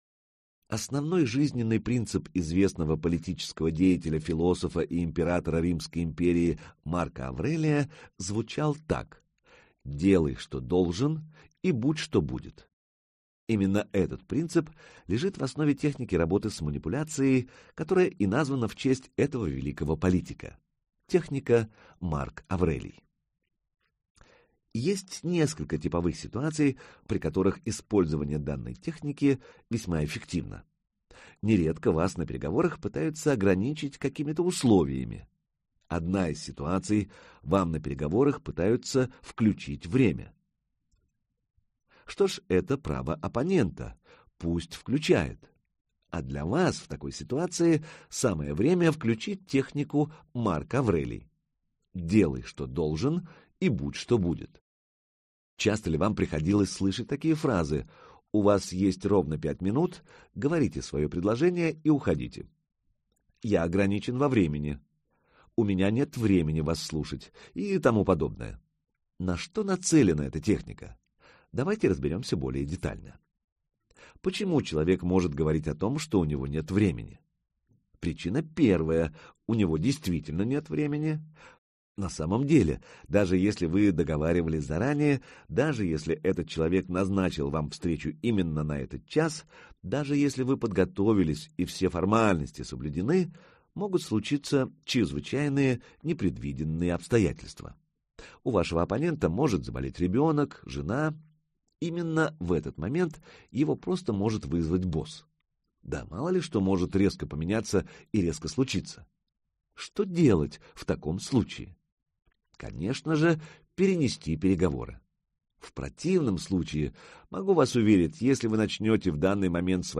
Аудиокнига Кремлевская школа переговоров - купить, скачать и слушать онлайн | КнигоПоиск